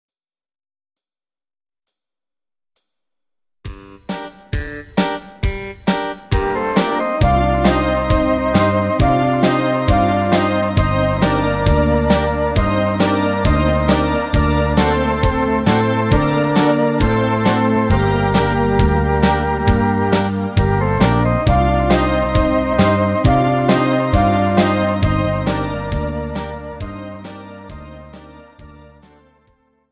Without Backing Vocals. Professional Karaoke Backing Tracks.
Folk , Pop